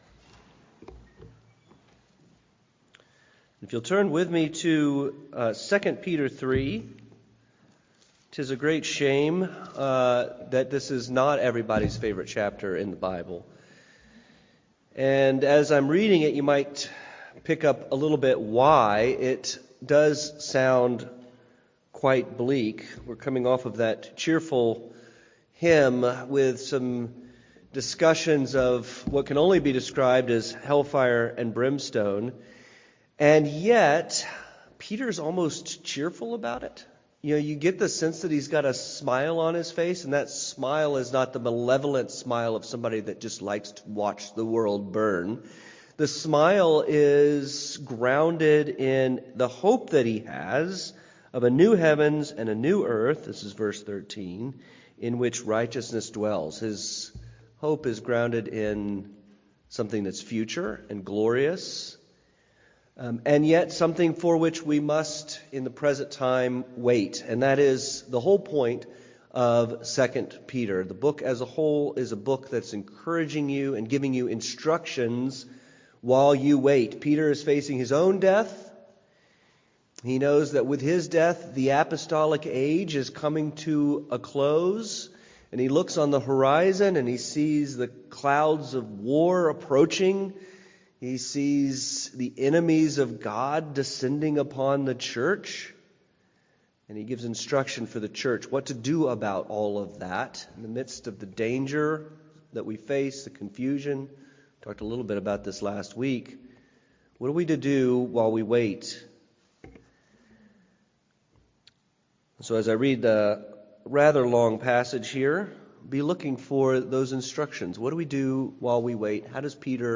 Waiting on the World to Change: Sermon on 2Peter 3 - New Hope Presbyterian Church